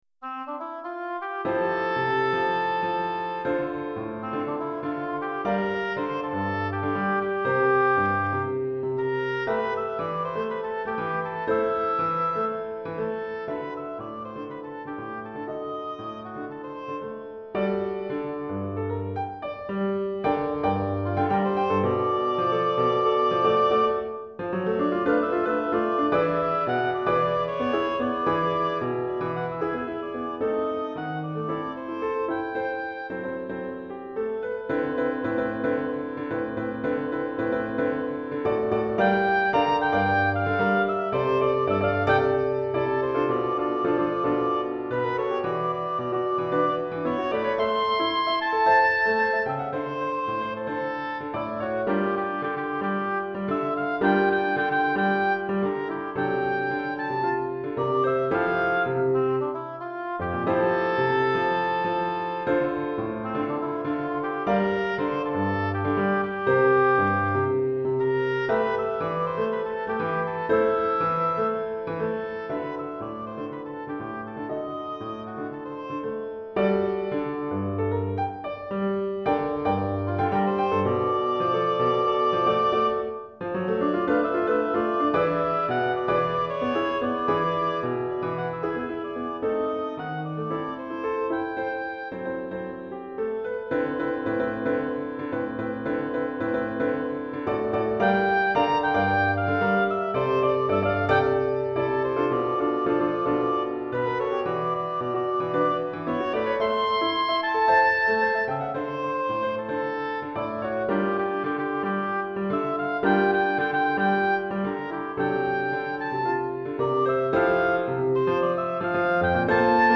Hautbois et Piano